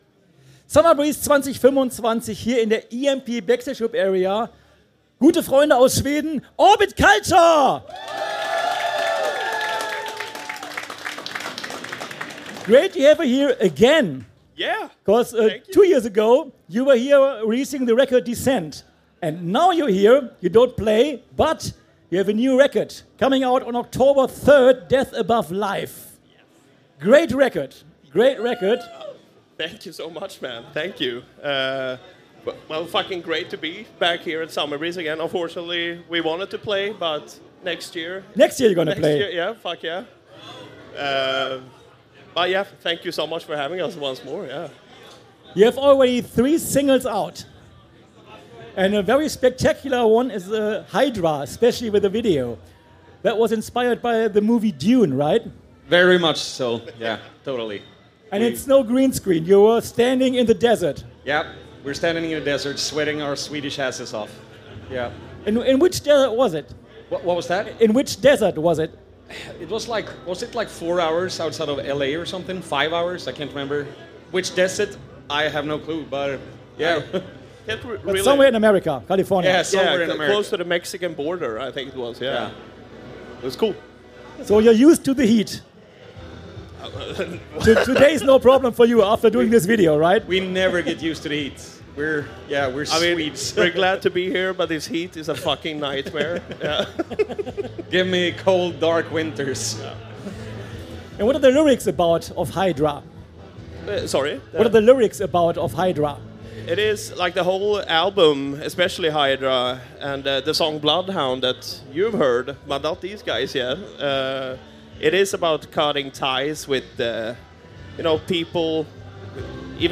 Summer Breeze 2025 Special - Orbit Culture- Live aus der EMP Backstage Club Area